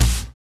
Belong To The World Snare.wav